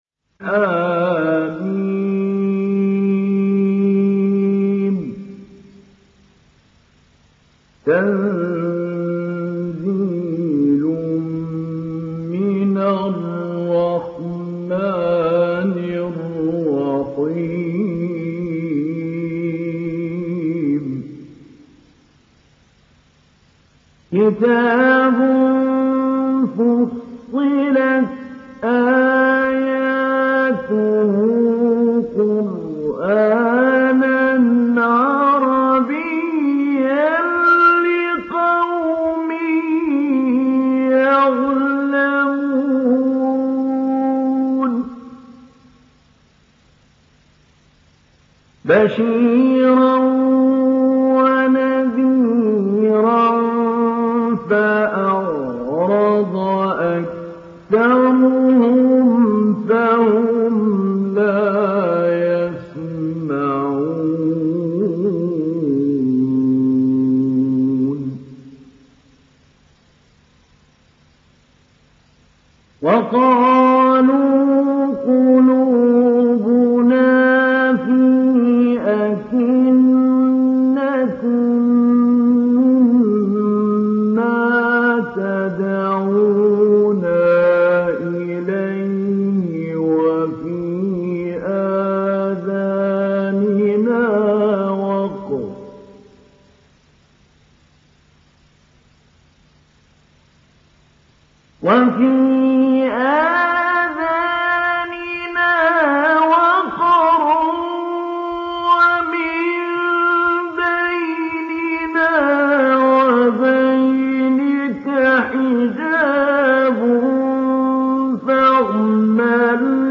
Download Surah Fussilat Mahmoud Ali Albanna Mujawwad